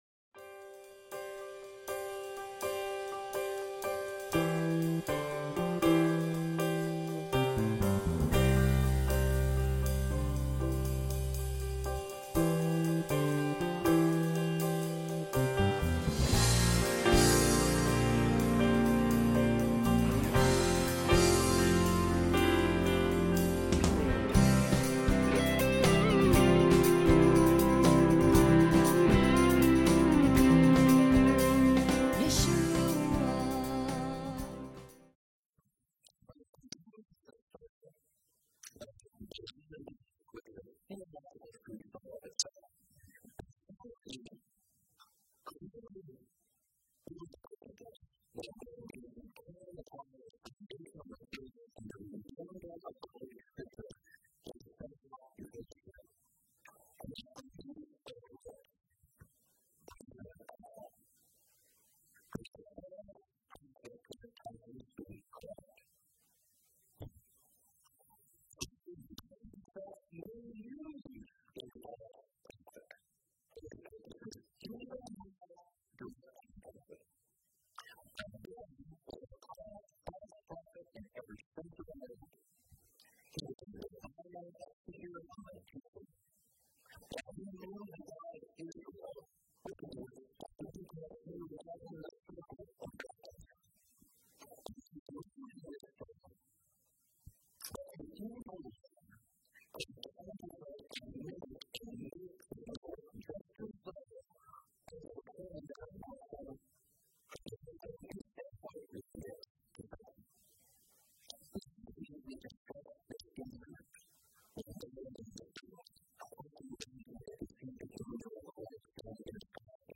Lesson 28 Ch23 Ch24 - Torah Class